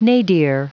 Prononciation du mot nadir en anglais (fichier audio)